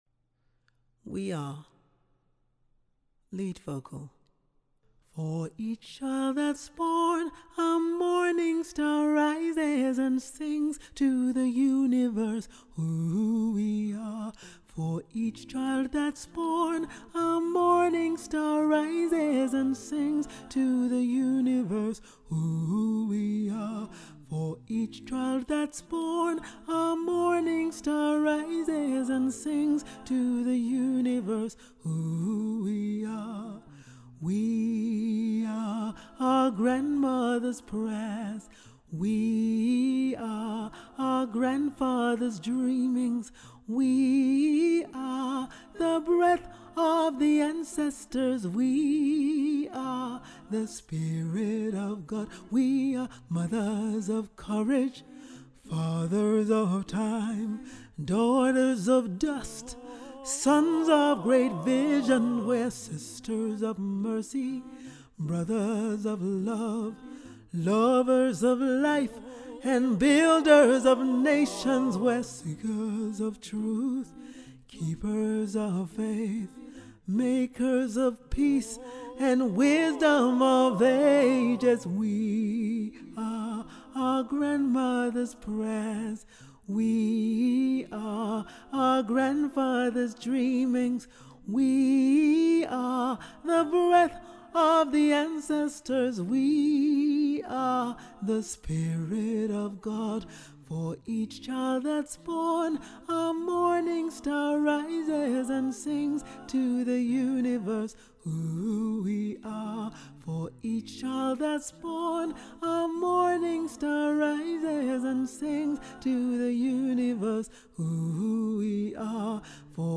Track 2 - Three Valleys Gospel Choir
We-are-lead-Vocal.mp3